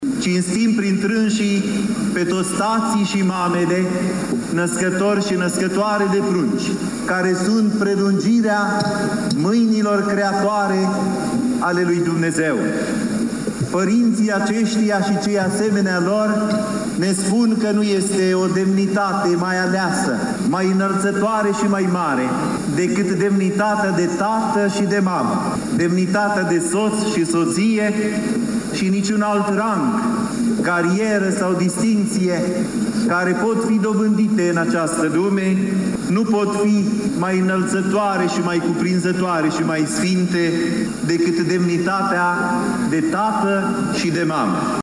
Peste 5000 de credincioşi veniţi la Iaşi din toată ţara şi din străinătate pentru a lua parte la hramul Sfintei Cuvioase Parascheva, au participat la slujba Sfintei Liturghii oficiată pe o scenă special amenajată la intrarea în curtea Mitropoliei Moldovei şi Bucovinei.